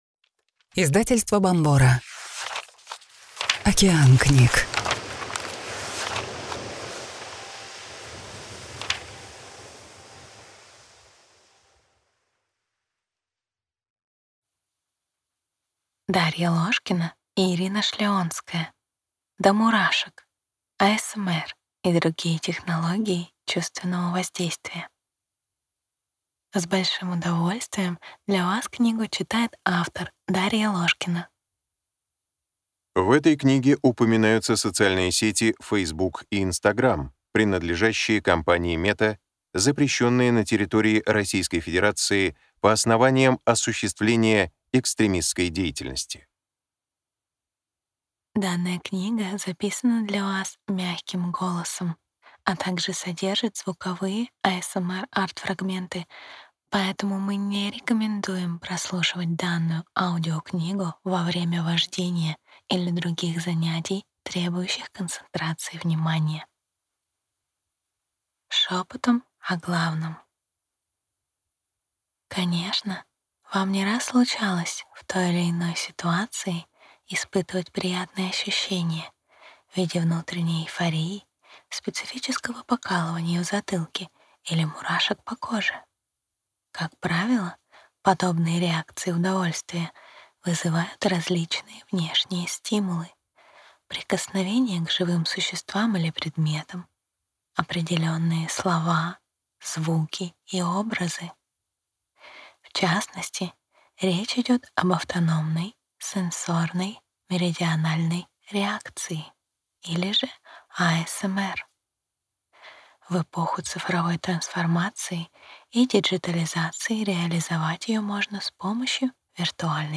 Аудиокнига До мурашек. ASMR и другие технологии чувственного воздействия | Библиотека аудиокниг